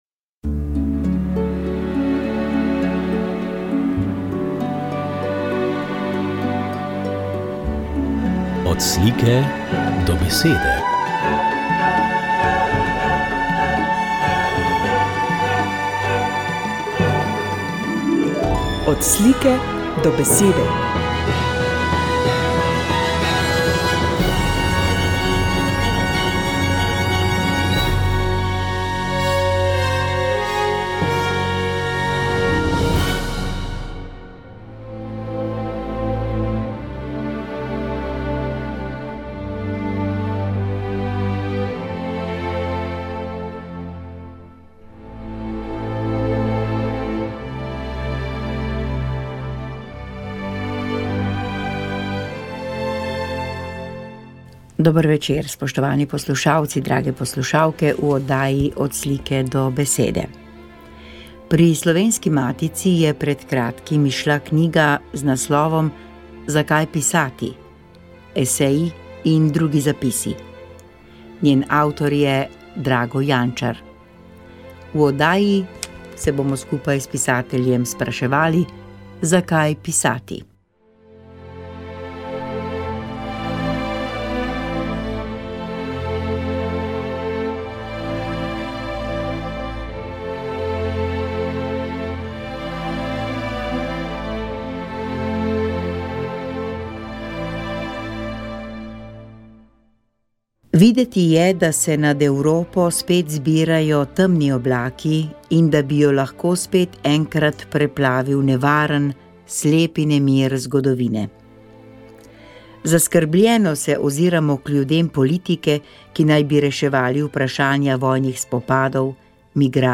Sv. maša iz cerkve Marijinega oznanjenja na Tromostovju v Ljubljani 14. 10.